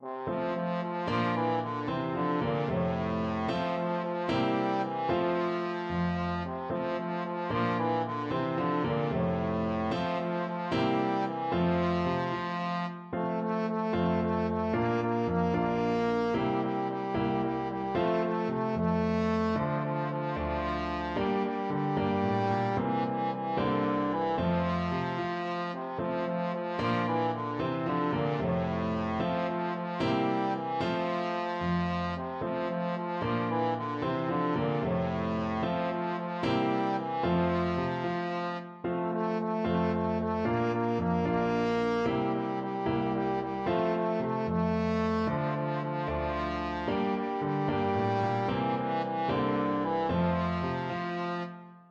Traditional Trad. Vive la Compagnie Trombone version
Trombone
Traditional Music of unknown author.
F major (Sounding Pitch) (View more F major Music for Trombone )
6/8 (View more 6/8 Music)
Allegro = c. 112 (View more music marked Allegro)